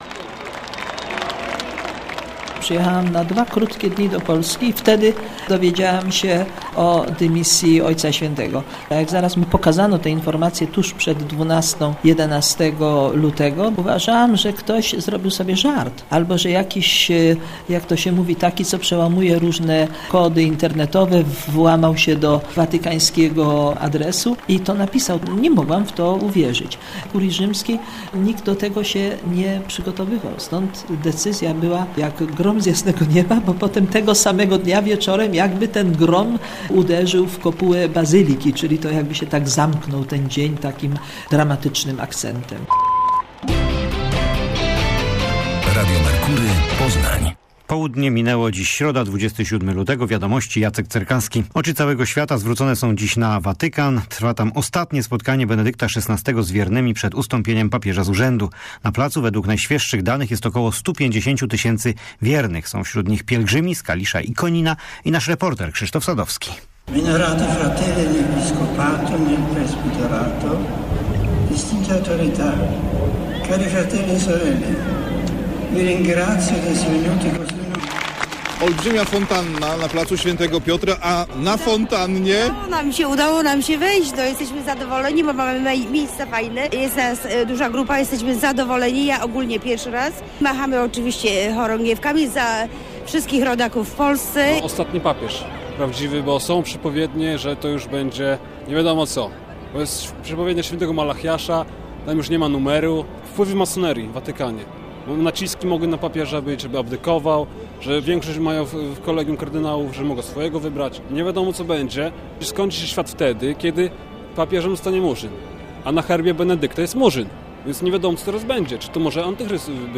Jak grom z jasnego nieba - reportaż